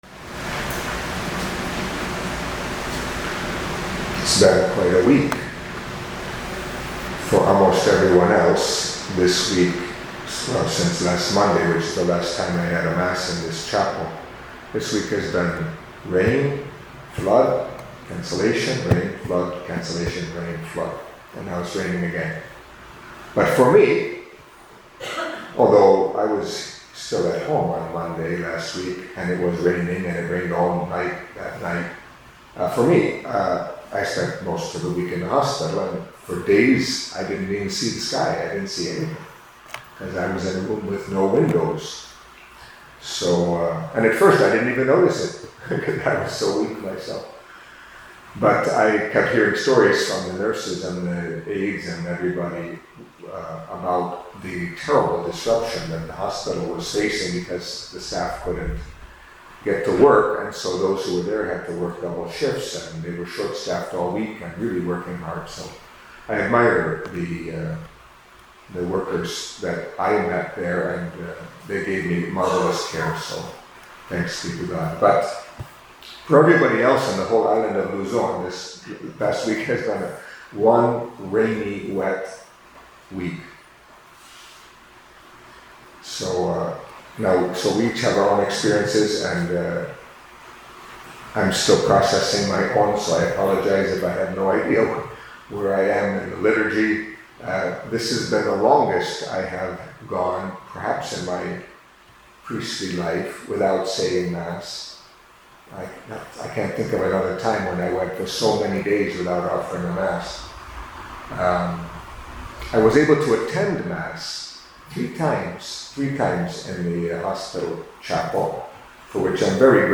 Catholic Mass homily for Monday of the Seventeenth Week in Ordinary Time